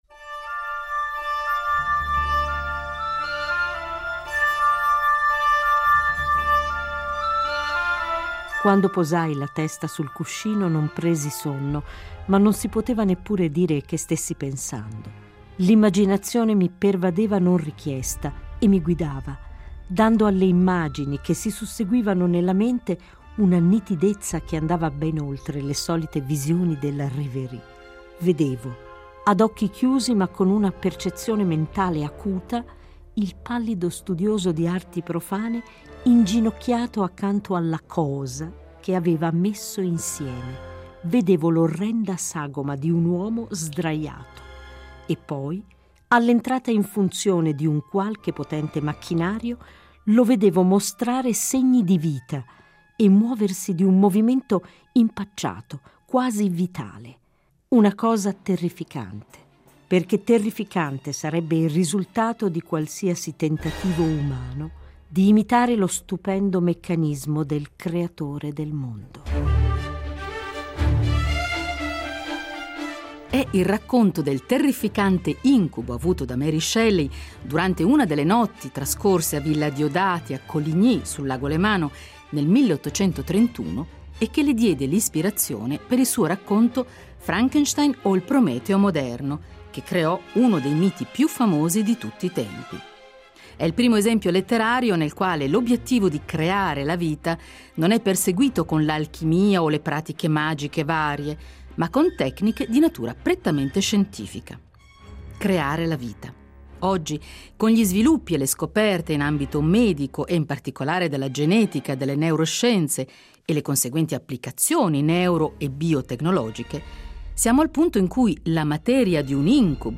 Giovedì 12 aprile sentiremo Sergio Canavero , che in una lunga intervista ha rivelato, in anteprima, aspetti di sé e del suo progetto davvero sorprendenti; per esempio il suo rifiuto del determinismo e la sua convinzione dell’immortalità della coscienza; o ancora che il trapianto di testa sarebbe solo il primo passo verso qualcosa di ancora più estremo: la clonazione del proprio corpo, così da allungare la vita umana e perché no, assicurarsi l’immortalità.